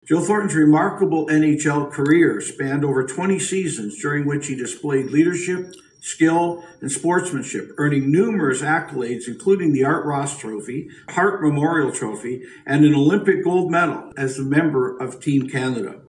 In reading the proclamation at city hall, Preston praised Thornton for his dedication, talent and love of the game.